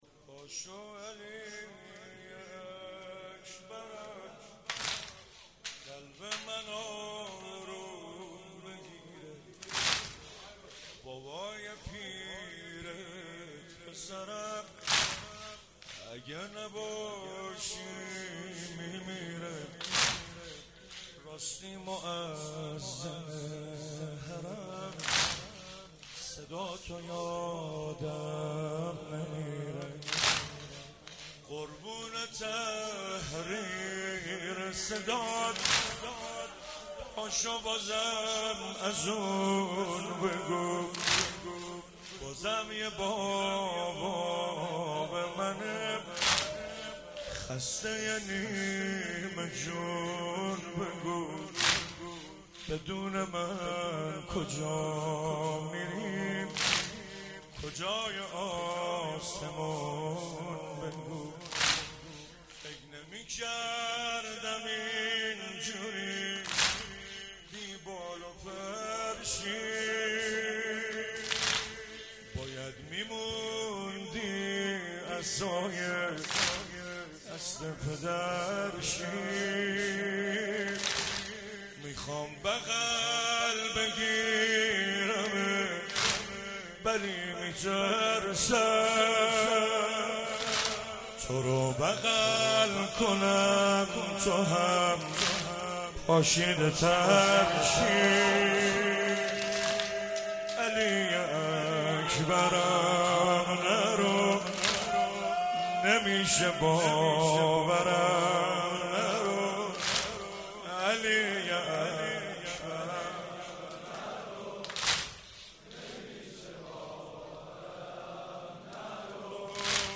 6 0 شب هشتم محرم97